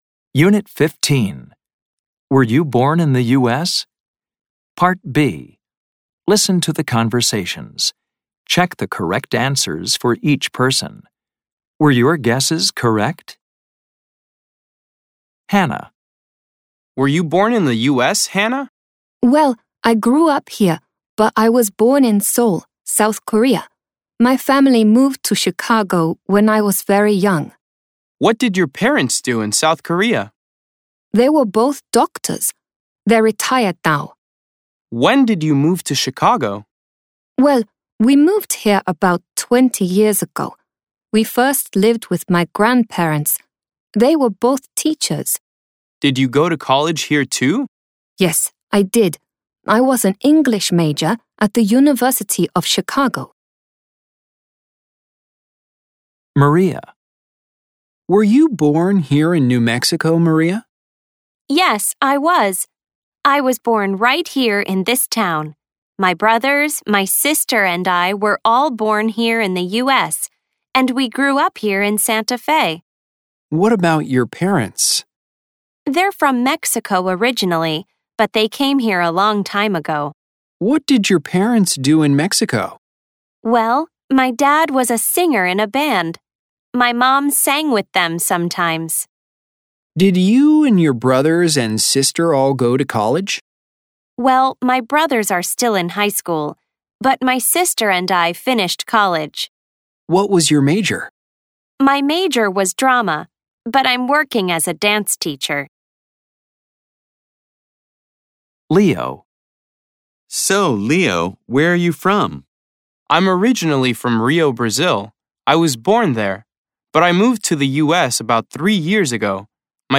American English
This includes Snapshots, Conversations, Grammar Focus, Listening, Pronunciation practice, Word Power, and Reading, all recorded in natural conversational English.